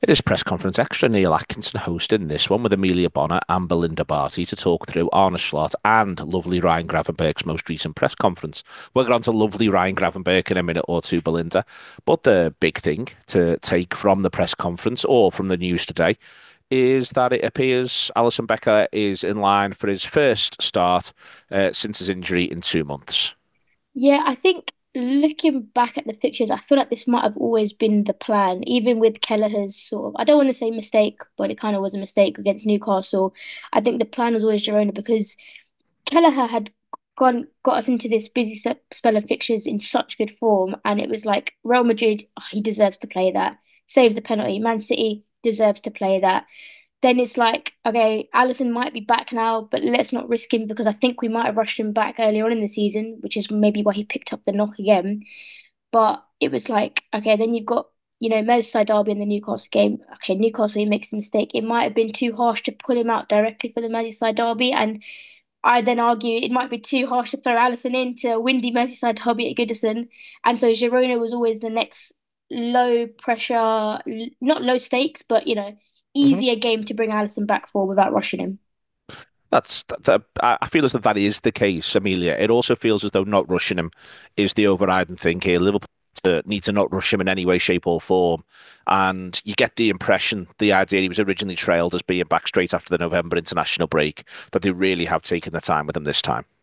Below is a clip from the show – subscribe for more on the Girona v Liverpool press conference…